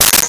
Sword Hit 03
Sword Hit 03.wav